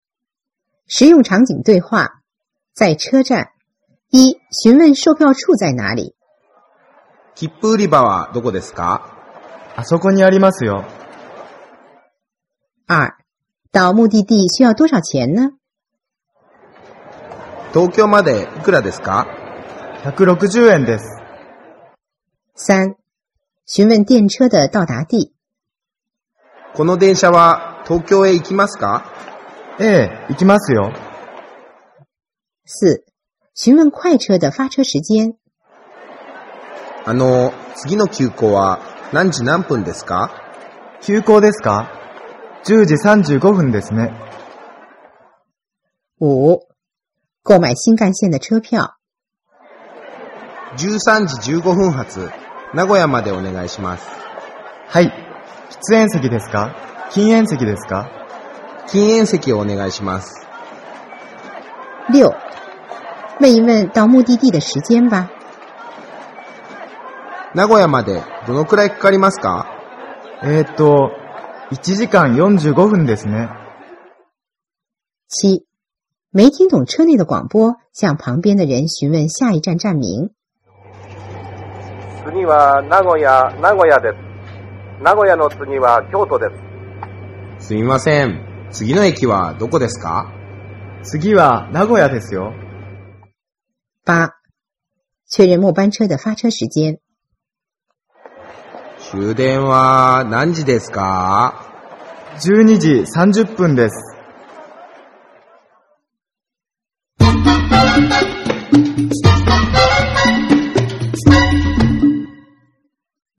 實用場景對話 2 — 在車站
Unit02Dialog.mp3